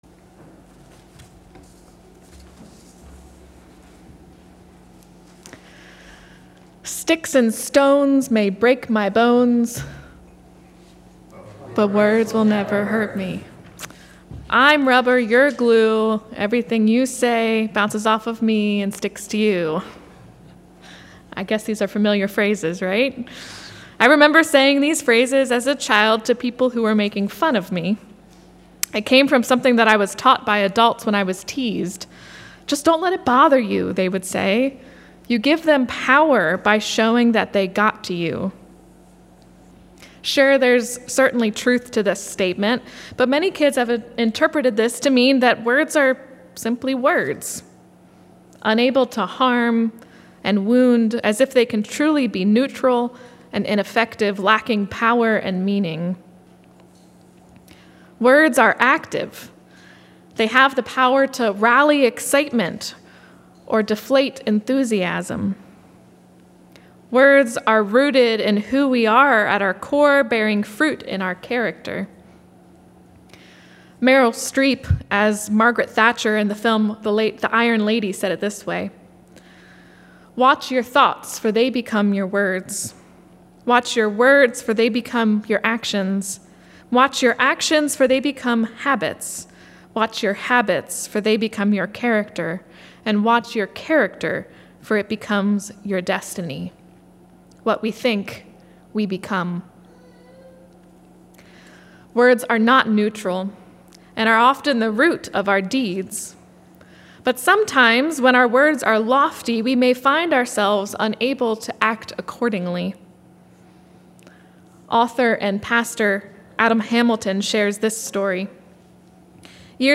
Service Type: Sunday Sermon